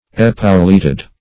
Search Result for " epauleted" : The Collaborative International Dictionary of English v.0.48: Epauleted \Ep"au*let`ed\, Epauletted \Ep"au*let`ted\, a. Wearing epaulets; decorated with epaulets.